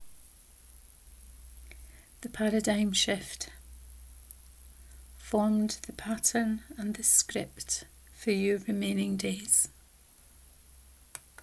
The Paradigm Shift 1 : Reading of this post